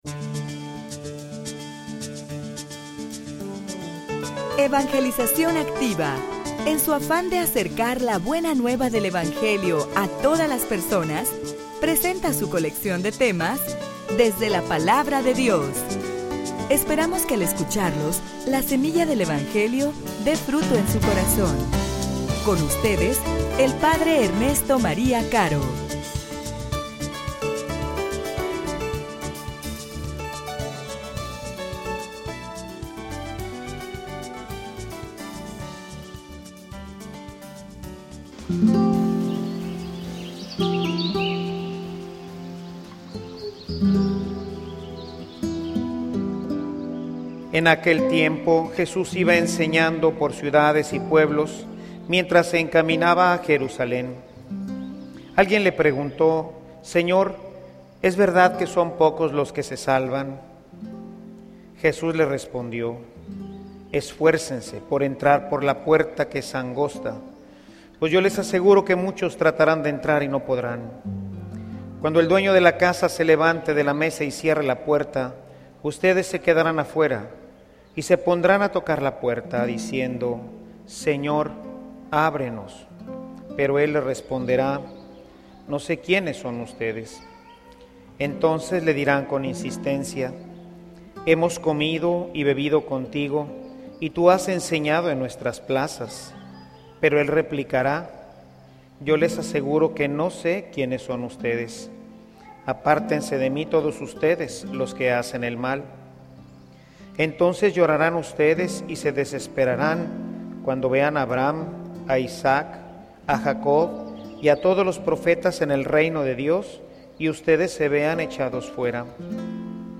homilia_Esfuercense.mp3